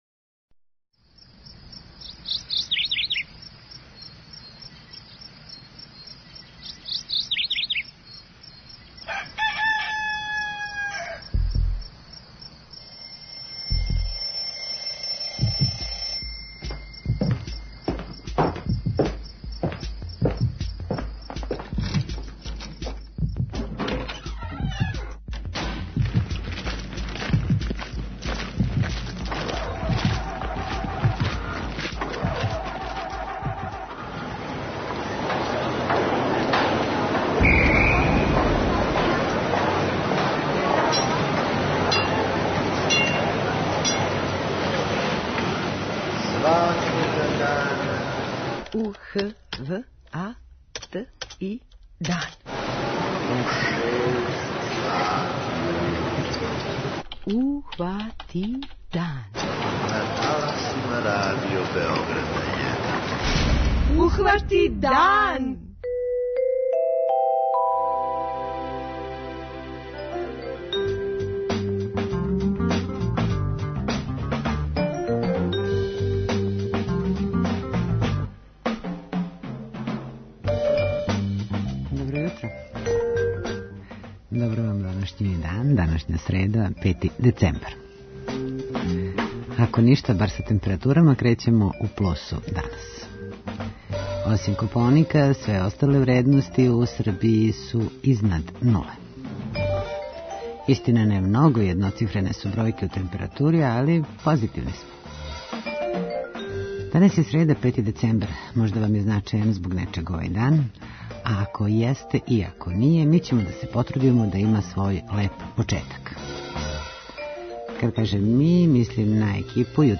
06:30 Догодило се на данашњи дан, 07:00 Вести, 07:05 Добро јутро децо, 08:00 Вести, 08:05 Српски на српском, 08:15 Гост јутра
У госте ће нам доћи и представници Волонтерског сервиса Србије - 5. децембар је Међународни дан волонтера.